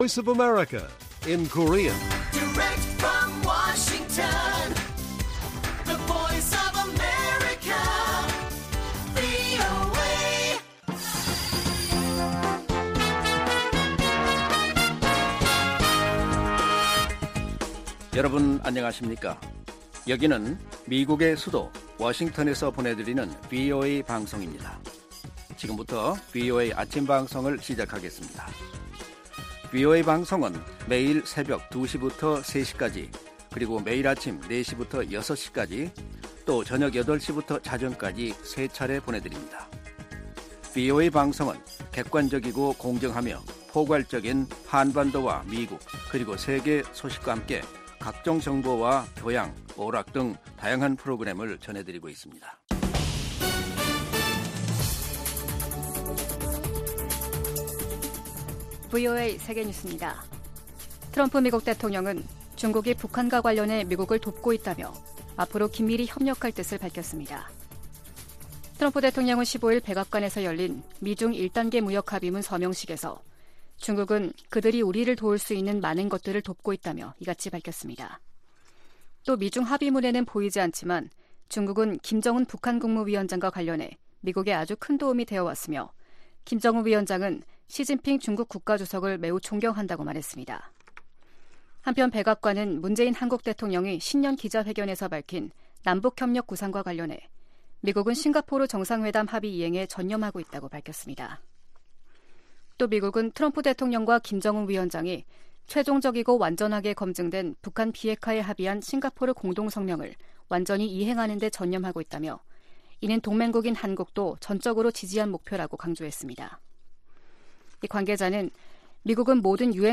세계 뉴스와 함께 미국의 모든 것을 소개하는 '생방송 여기는 워싱턴입니다', 2020년 1월 17일 아침 방송입니다. ‘지구촌 오늘’에서는 블라디미르 푸틴 러시아 대통령이 개헌 의지를 밝힌 가운데 총리를 포함한 내각이 총사퇴를 발표했다는 소식, ‘아메리카 나우’에서는 트럼프 대통령 탄핵안이 상원에 제출됐습니다.